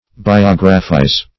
Biographize \Bi*og"ra*phize\, v. t. To write a history of the life of.